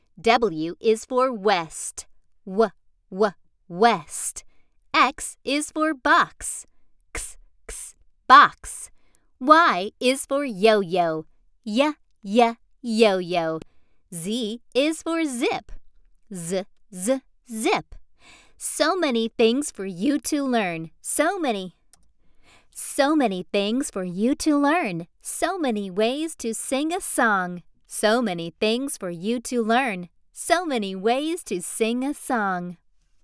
英语样音试听下载